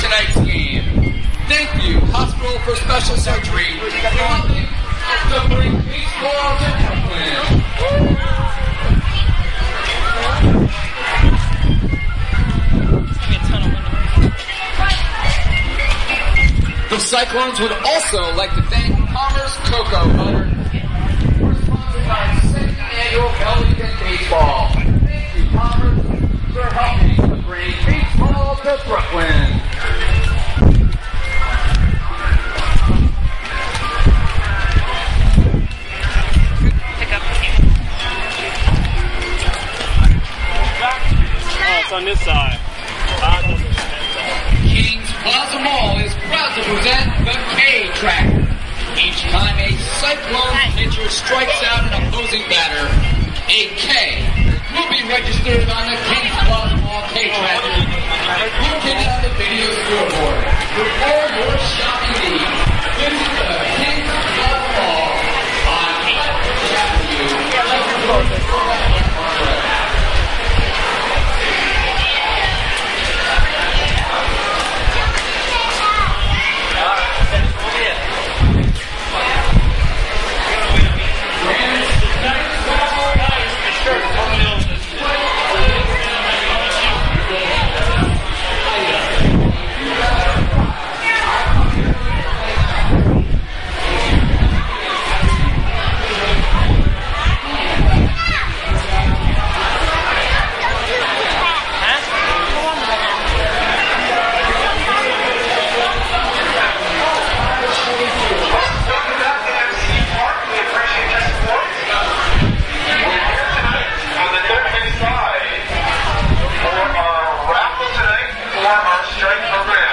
描述：在独眼巨人体育场周围行走的现场录音，背景中播放着公告。
Tag: 氛围 体育场